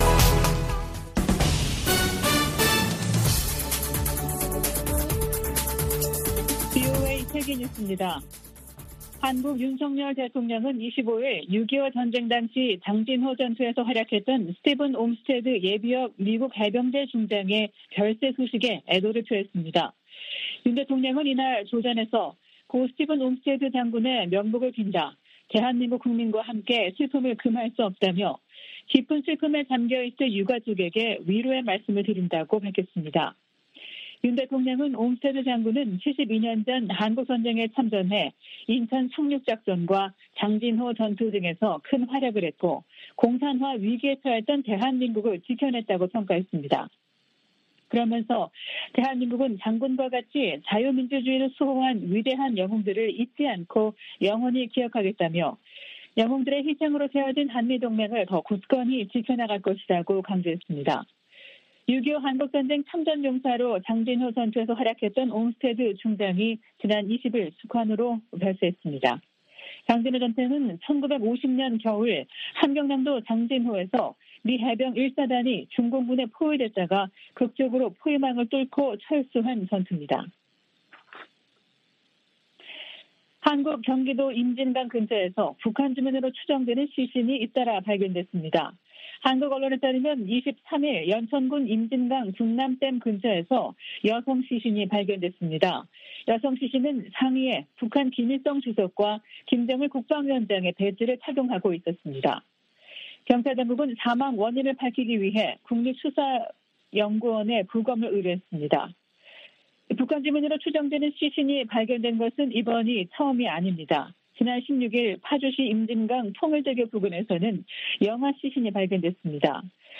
VOA 한국어 아침 뉴스 프로그램 '워싱턴 뉴스 광장' 2022년 7월 26일 방송입니다. 미 국무부의 빅토리아 눌런드 정무차관은 러시아와 북한의 밀착이 러시아의 고립을 보여주는 것이라고 평가했습니다. 일본과 아일랜드 정상이 북한의 탄도미사일 발사를 규탄하며 대량살상무기 완전 폐기를 촉구했습니다. 사이버 공격 대응을 위한 정부의 노력을 강화하도록 하는 ‘랜섬웨어 법안’이 미 하원 상임위원회를 통과했습니다.